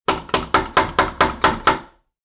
Звук стука в дверь для сказки
Кто-то стучится с помощью металлического молоточка.